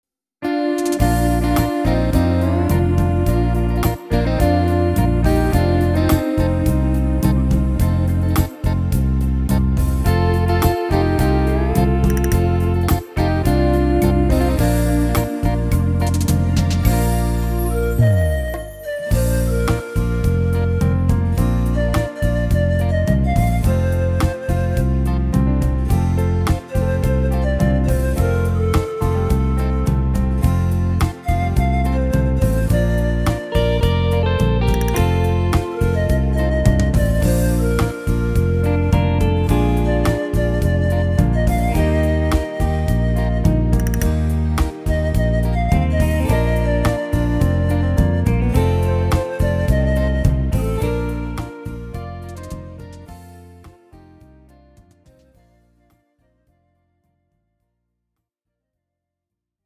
Tempo: 106 / Tonart: Bb – Dur
– 1 x MP3-Datei mit Melodie-Spur